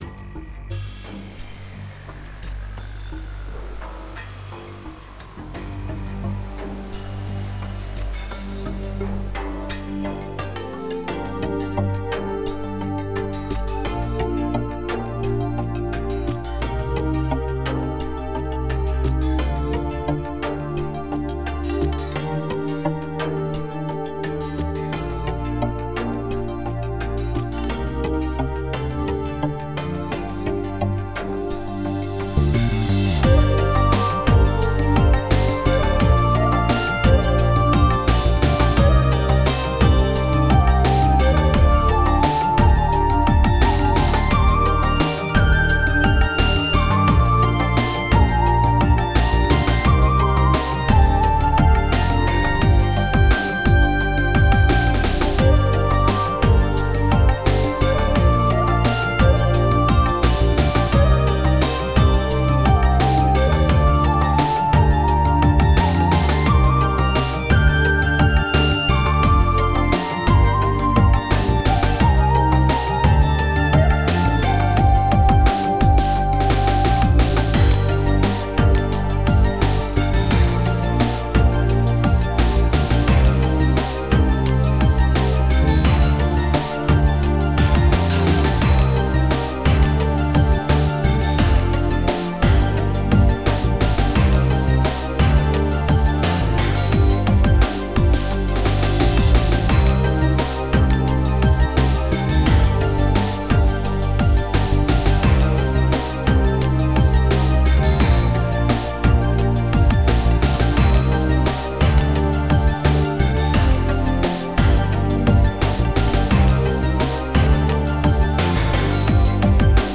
Style: New Age
An Enigma-ish sounding track